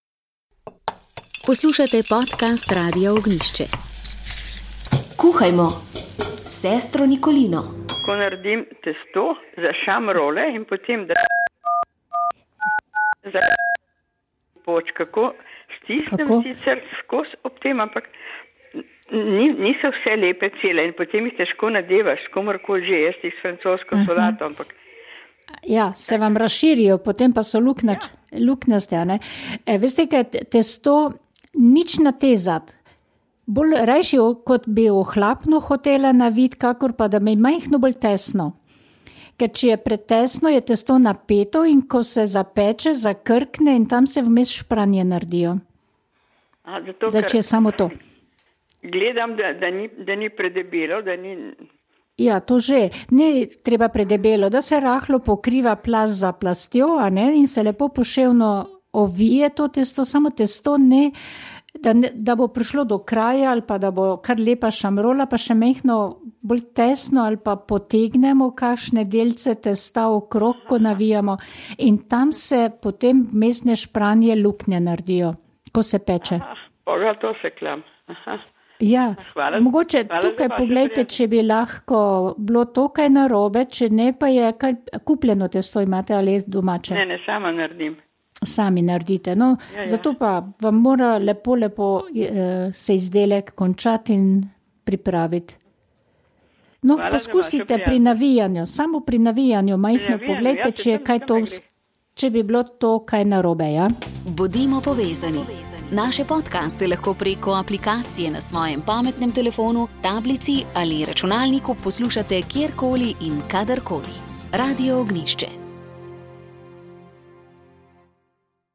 Pripravili smo pogovor o aktualnem dogajanju pri nas. Pred nekaj dnevi je bila spet spominska slovesnost v Dražgošah, kjer je bila slavnostna govornica predsednica Državnega zbora Urška Klakočar Zupančič.